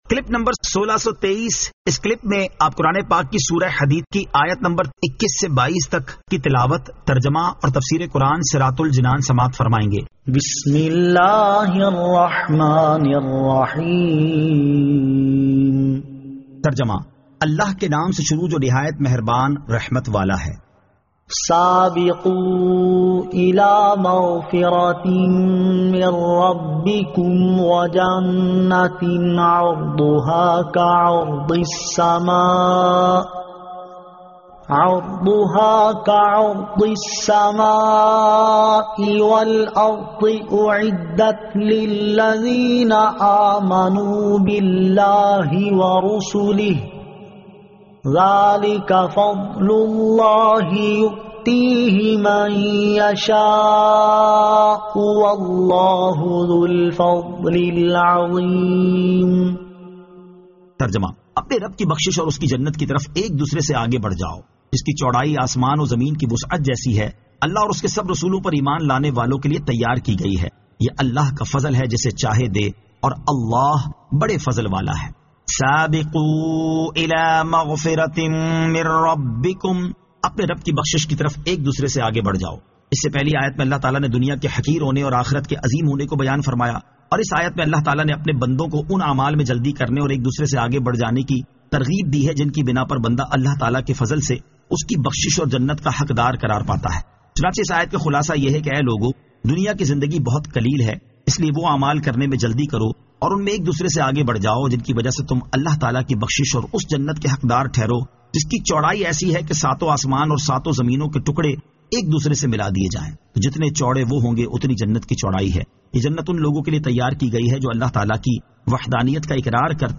Surah Al-Hadid 21 To 22 Tilawat , Tarjama , Tafseer